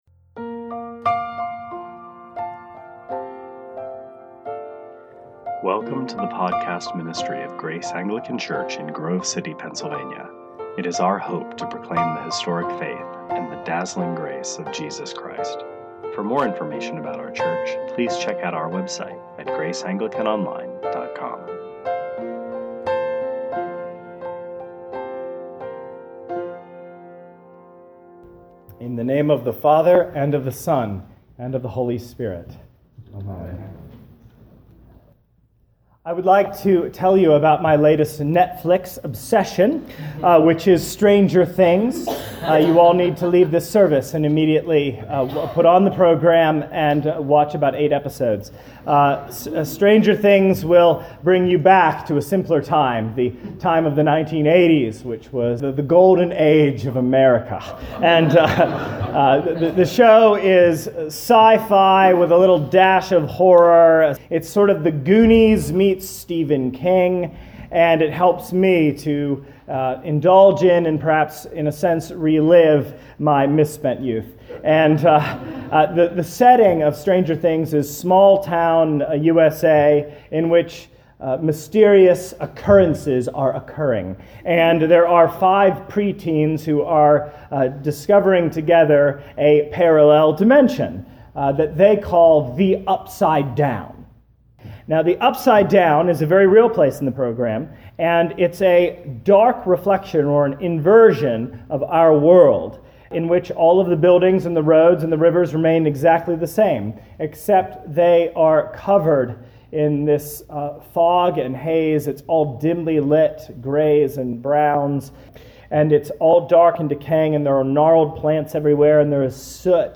2018 Sermons Stranger Things (without Winona Ryder) -Mark 1 Play Episode Pause Episode Mute/Unmute Episode Rewind 10 Seconds 1x Fast Forward 30 seconds 00:00 / 29:57 Subscribe Share RSS Feed Share Link Embed